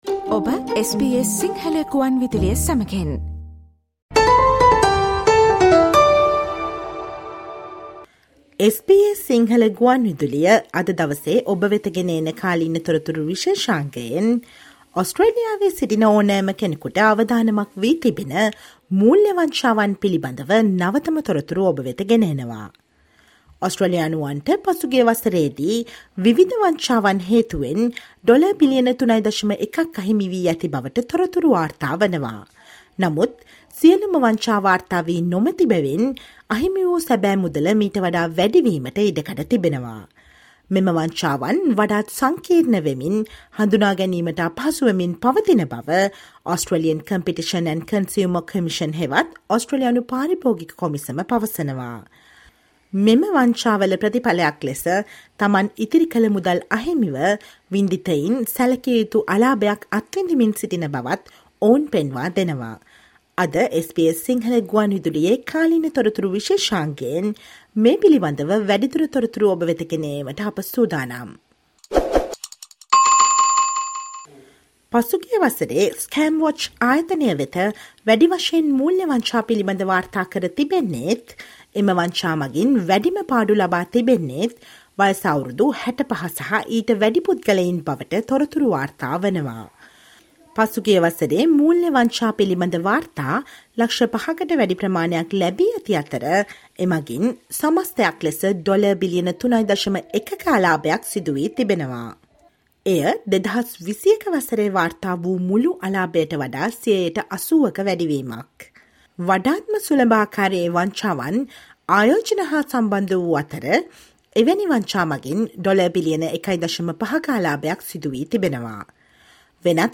Listen to the SBS Sinhala radio current affair feature on the latest updates on the most complex and sophisticated scams around and warnings by Australian competition and consumer commission